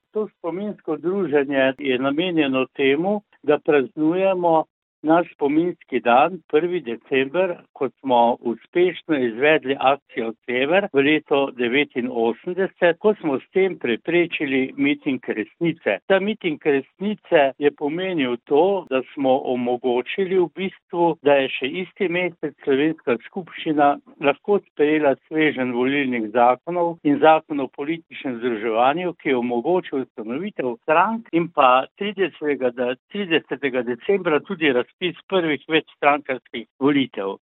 V večnamenski dvorani Lopan v Mislinji je včeraj potekal spominski dan Združenja Sever.